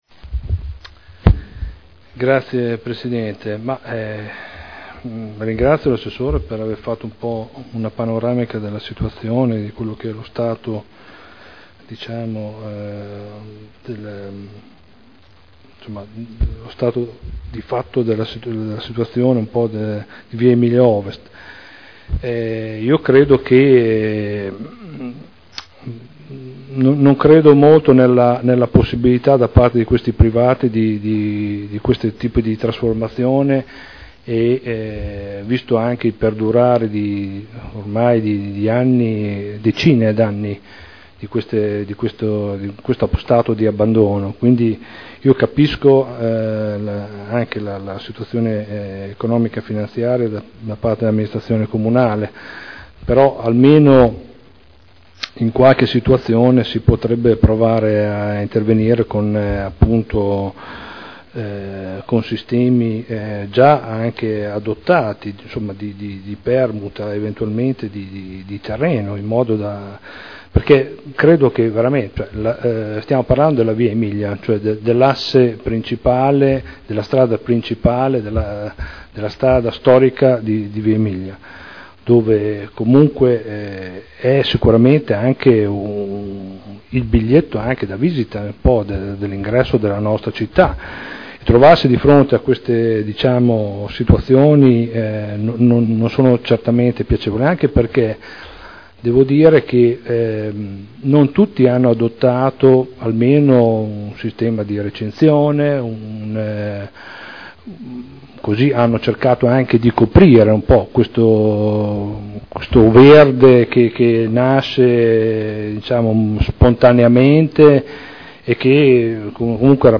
Stefano Prampolini — Sito Audio Consiglio Comunale